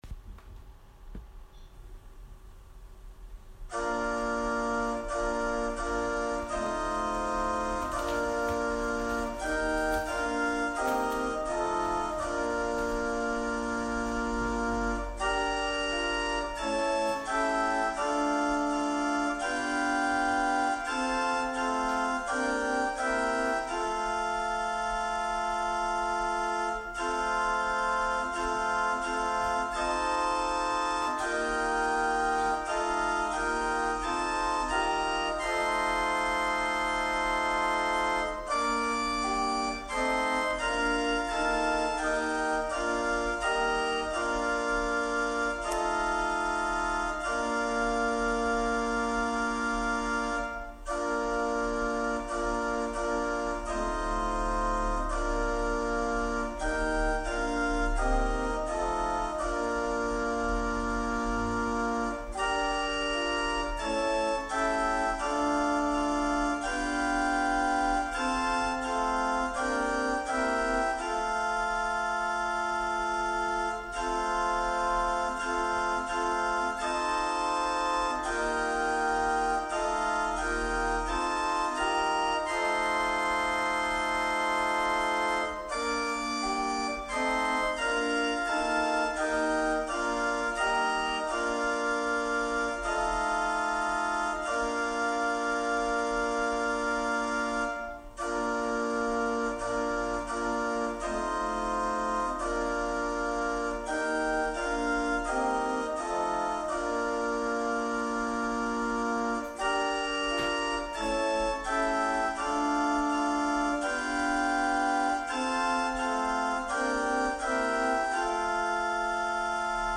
千間台教会。説教アーカイブ。